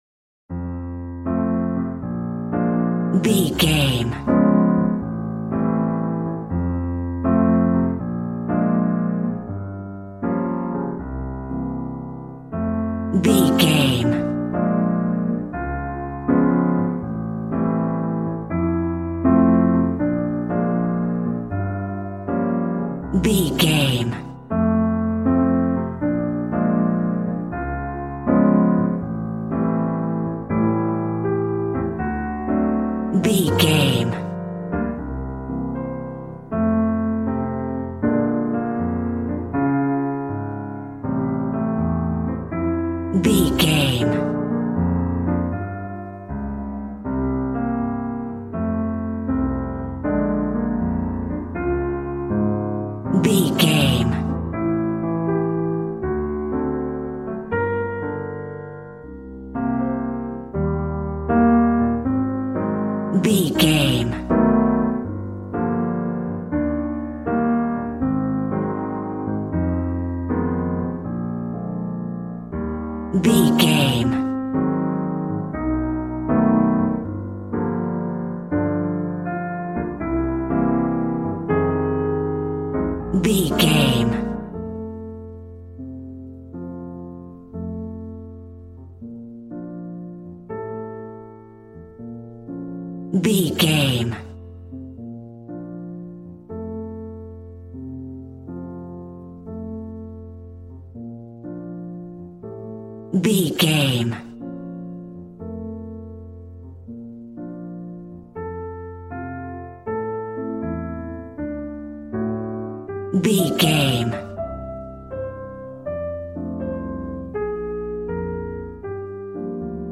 Ionian/Major